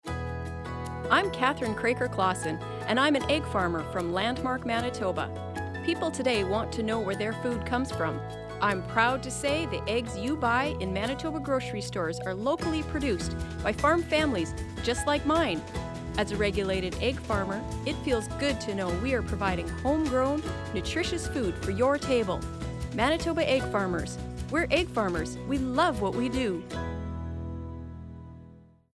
Egg farmers demonstrate their love for egg farming in these 30-second radio spots (MP3).